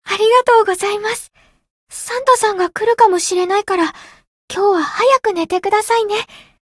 灵魂潮汐-梦咲音月-圣诞节（送礼语音）.ogg